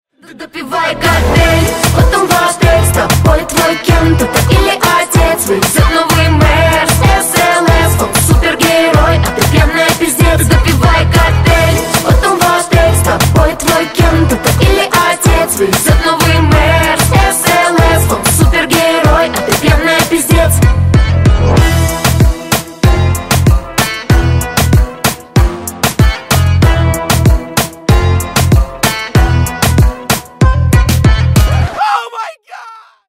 Поп Музыка # Танцевальные
весёлые